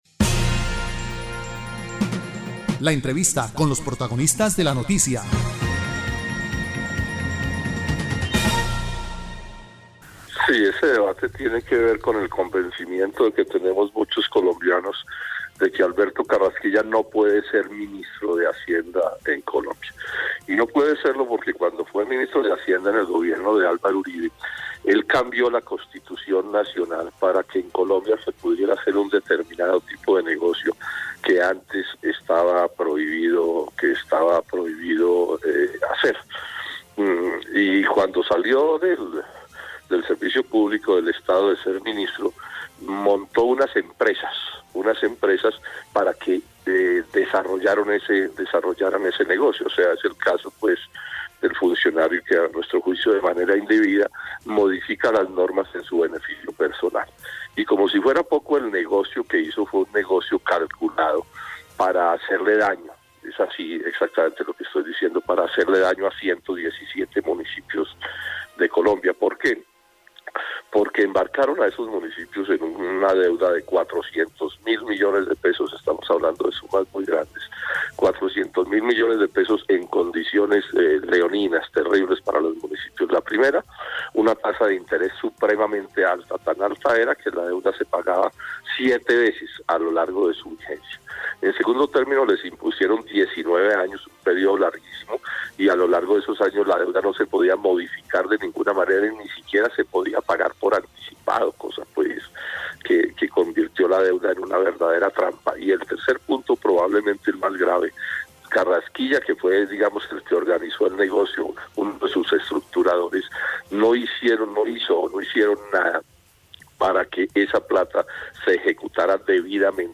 Entrevista a Jorge Robledo